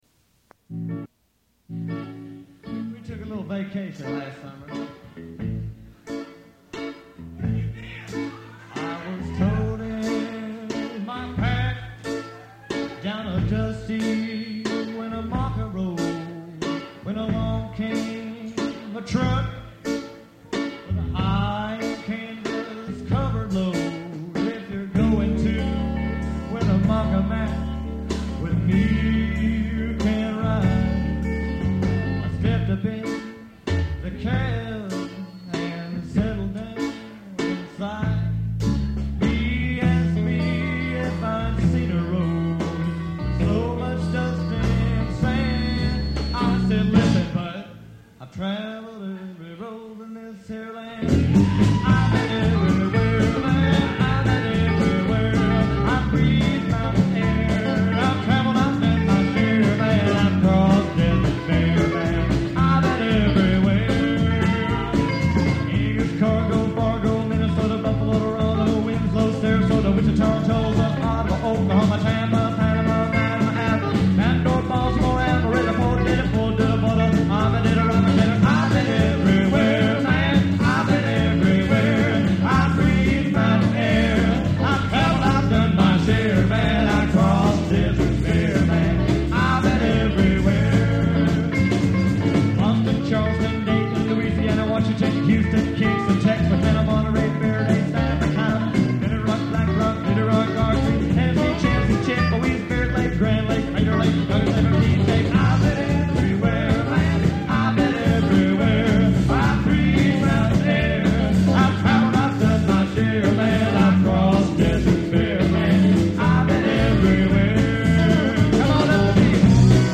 Tennessee Alternative Country and Western Swing band.
Their hard hitting swing beat was hard to beat.
The Faded Princes of Western Swing
Players:
Bass
Fiddles
Drums